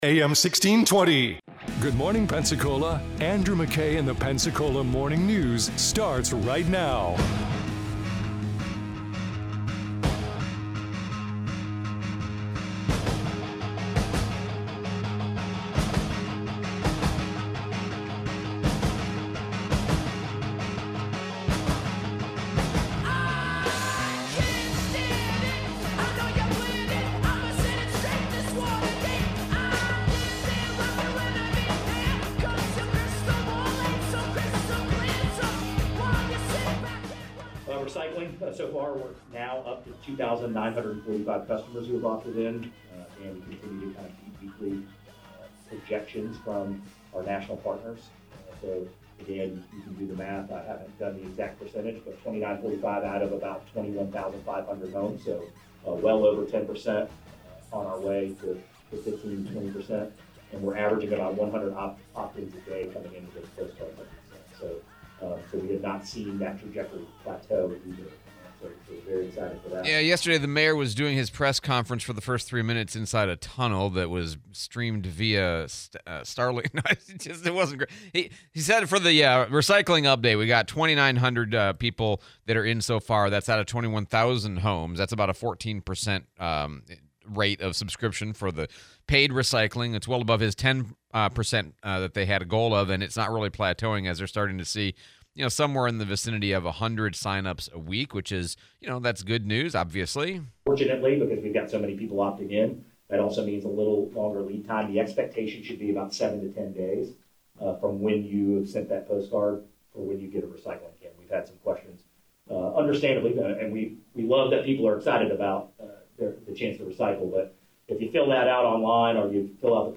City of Pensacola recycling, interview with Sheriff Simmons